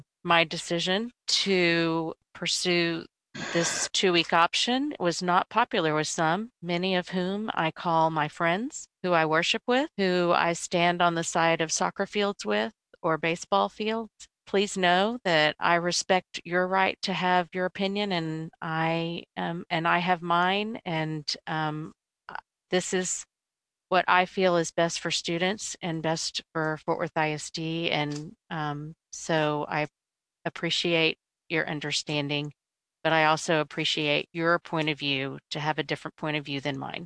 Fort Worth (WBAP/KLIF) – After a marathon nearly 11-hour virtual meeting, the Fort Worth Independent School District board of trustees early Wednesday voted to extend
District 6 Trustee Anne Darr proposed the compromise: